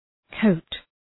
Προφορά
{kəʋt}